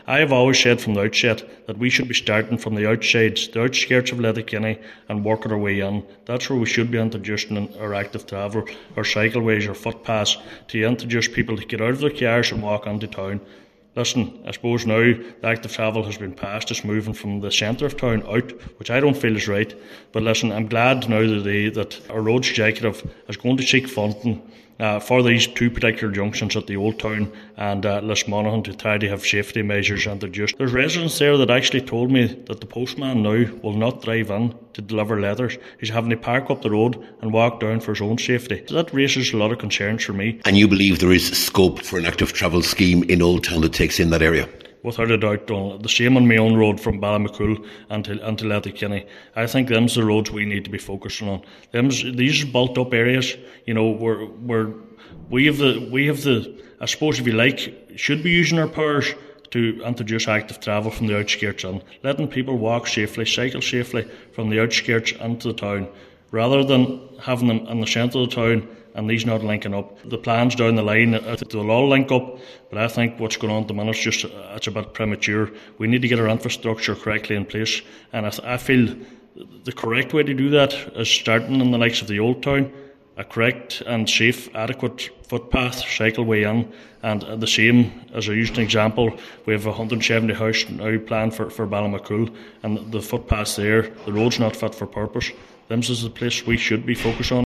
Cllr Kelly says safety measures are needed……………